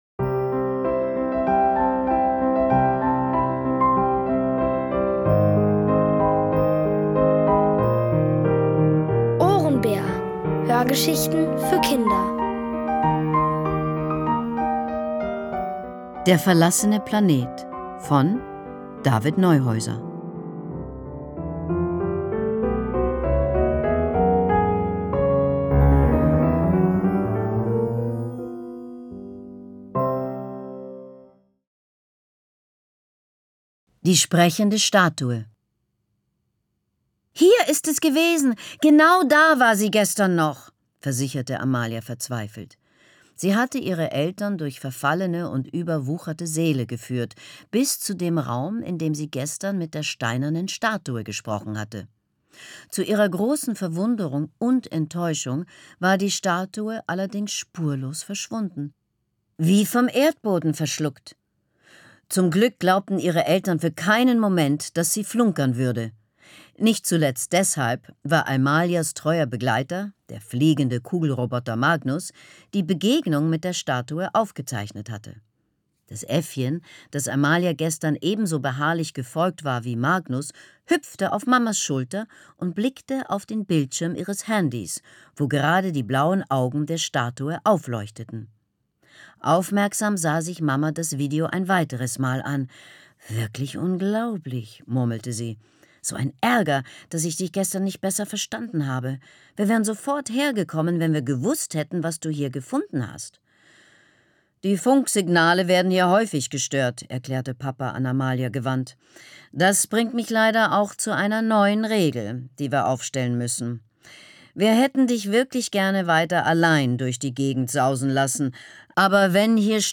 Von Autoren extra für die Reihe geschrieben und von bekannten Schauspielern gelesen.
Es liest: Leslie Malton.